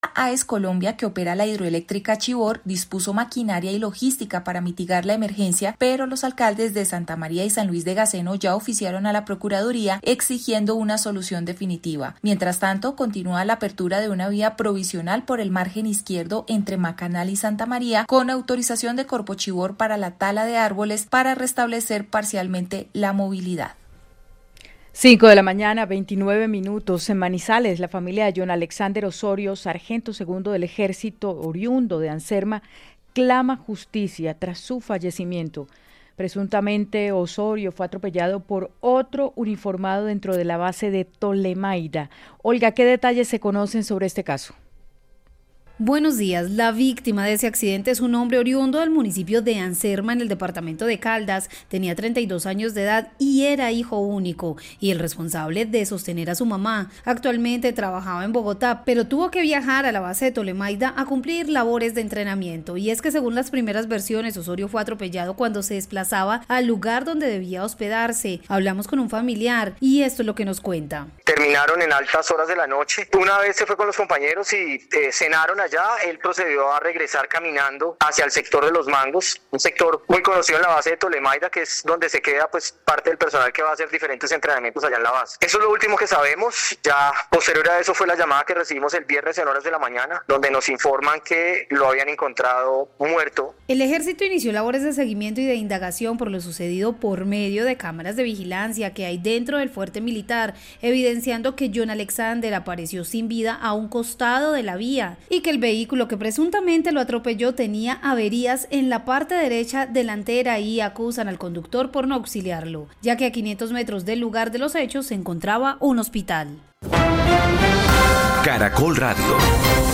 En 6AM de Caracol Radio estuvo Isabelita Mercado, Consejera de Paz, Víctimas y Reconciliación de Alcaldía Bogotá, quien explicó qué pasará con la discordia de la ubicación en el predio de Ciudad Salitre.